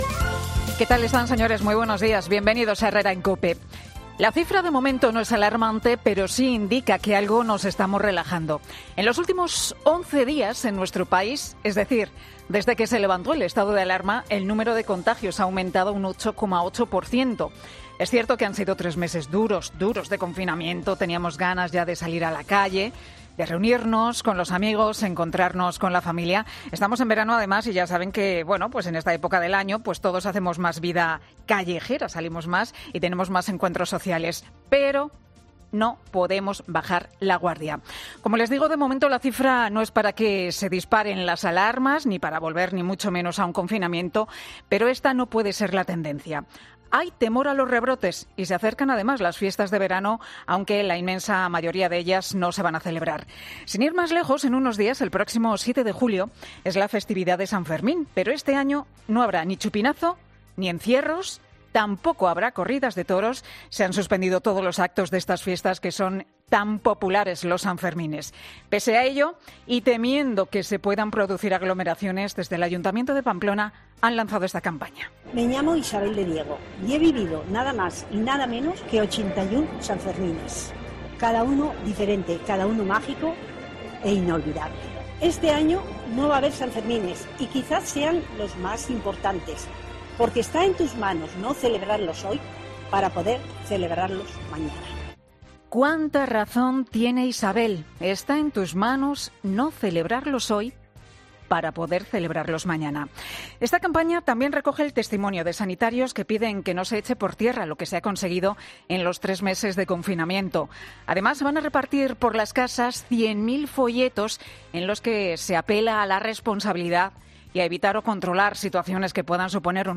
AUDIO: El análisis de actualidad de Pilar García Muñiz